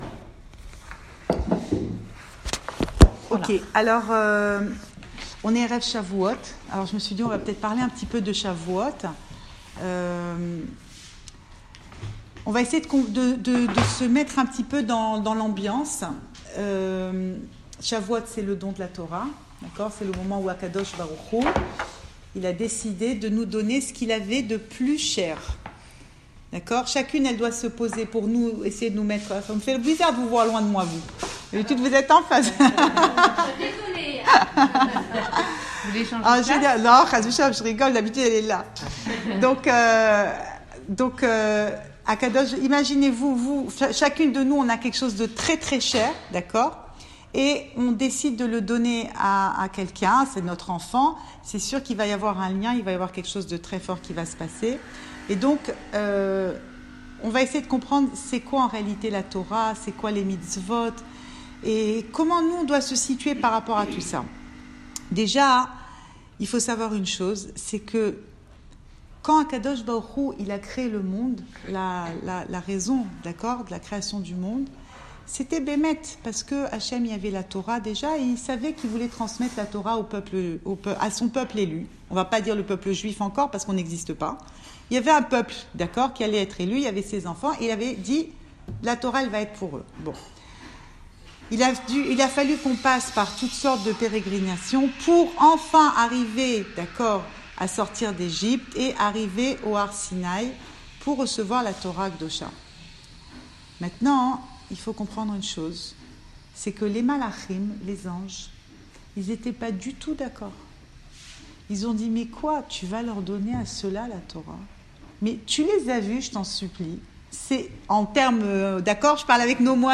Cours audio Fêtes Le coin des femmes - 5 juin 2019 7 juin 2019 Chavouot : La Torah, vous la voulez ou pas ?! Enregistré à Tel Aviv